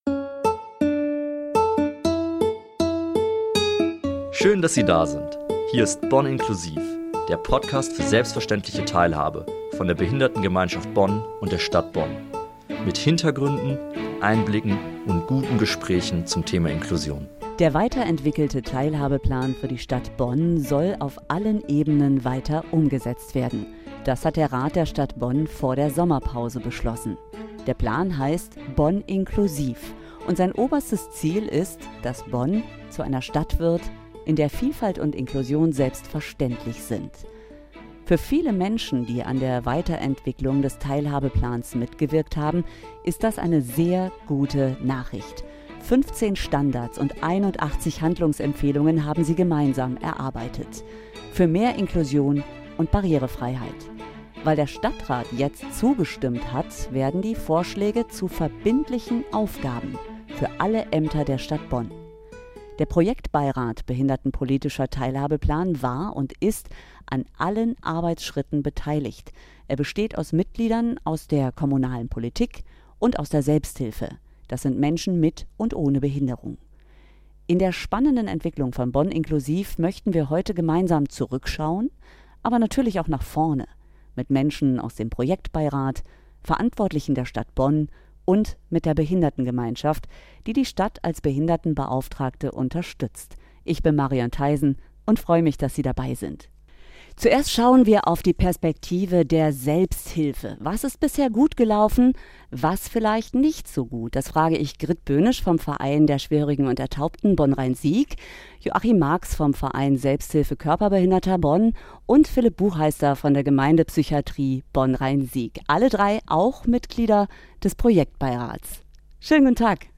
In diesem Podcast erzählen Politikerinnen, Menschen aus Vereinen, die Stadt Bonn und die Behinderten-Gemeinschaft Bonn, wie sie die Zusammenarbeit im Projektbeirat erlebt haben.